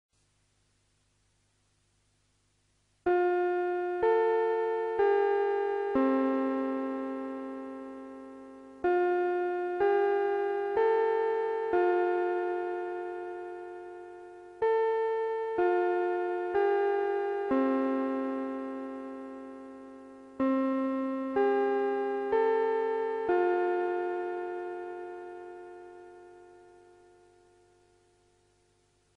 Japanese School Bell (Westminster Chime).mp3